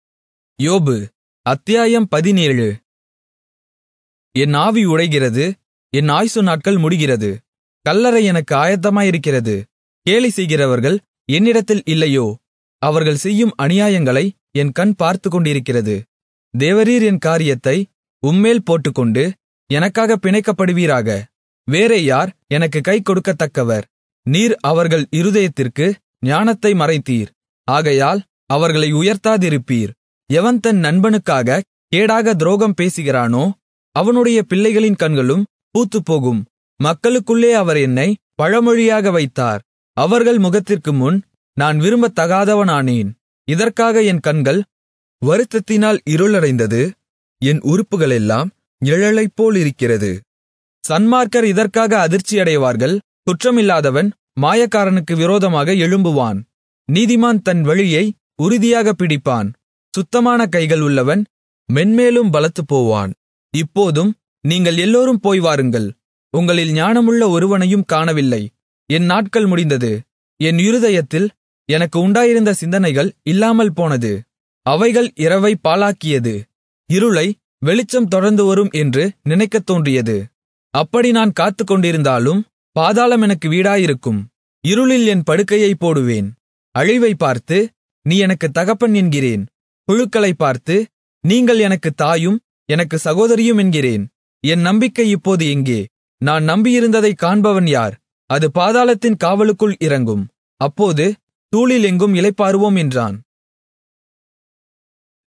Tamil Audio Bible - Job 36 in Irvta bible version